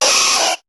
Cri de Nidoran♂ dans Pokémon HOME.